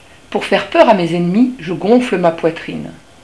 Dès le mois d'avril je chante pour attirer ma femelle. J'aime vivre près des hommes, dans les jardins.